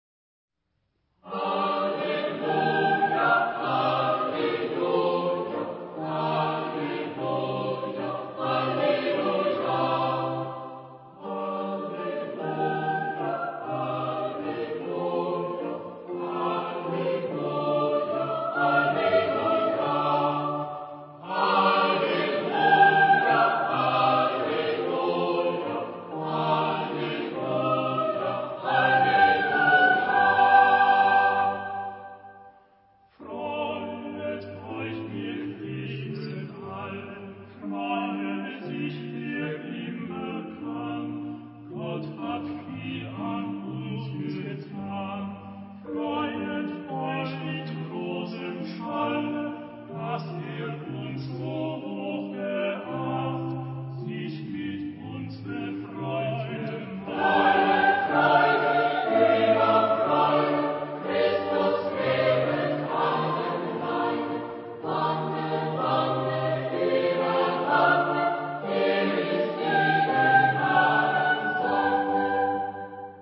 Genre-Style-Forme : Sacré ; Baroque ; Motet
Caractère de la pièce : joyeux
Type de choeur : SSATTTBB  (8 voix mixtes )
Instruments : Basse continue ; Contrebasse (1)
Tonalité : sol mineur
Knabenchor